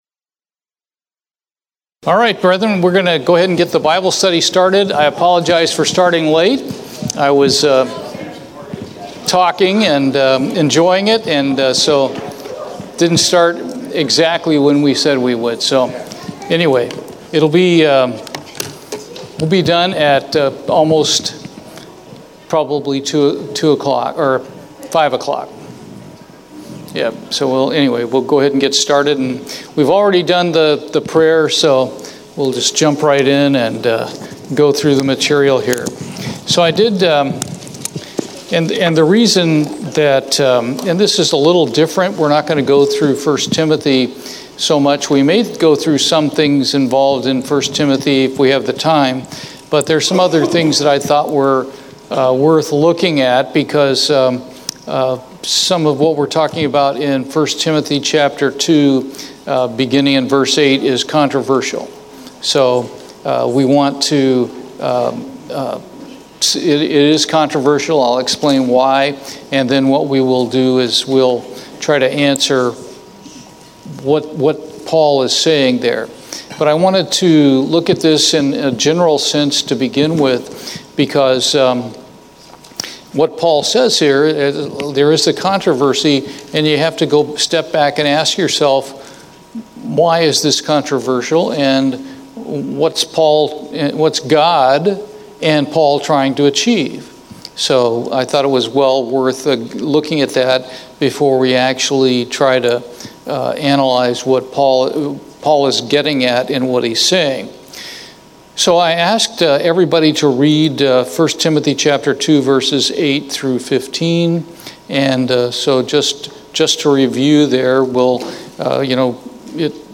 Bible Study, I Timothy 2:8-15